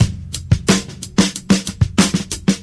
• 121 Bpm Breakbeat Sample C# Key.wav
Free drum beat - kick tuned to the C# note. Loudest frequency: 2020Hz
121-bpm-breakbeat-sample-c-sharp-key-e3G.wav